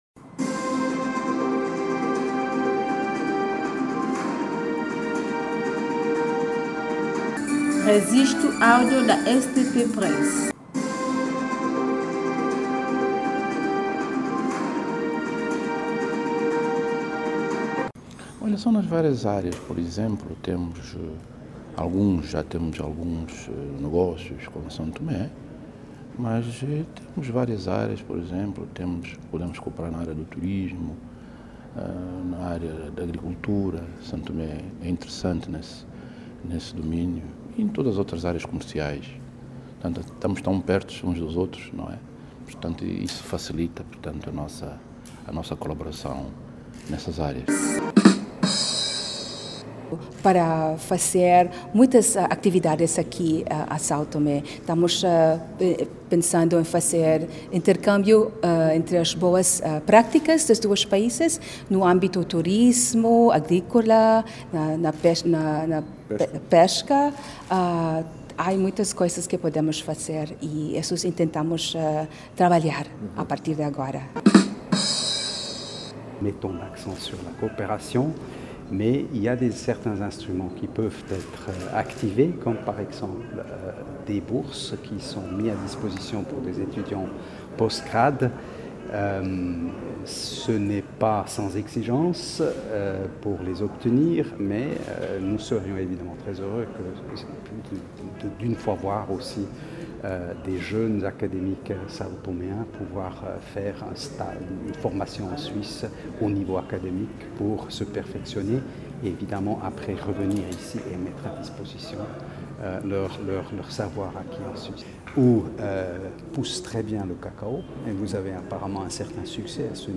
O novo embaixador de Angola para São-Tomé, Joaquim Duarte Pombo e a nova representante das Filipinas, Célia Anna Fereia bem como o novo diplomata da Suíça, Nicolas Lang fizerem estas declarações a saída de audiências, no Palácio Presidencial em São Tomé, após apresentação das respectivas cartas credenciais ao Presidente são-tomense, Evaristo Carvalho.
Embaixadores: 1º de Angola/ 2ª. das Filipinas / 3º. da Suíça